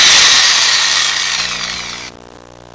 Drill Coming Down Sound Effect
Download a high-quality drill coming down sound effect.
drill-coming-down.mp3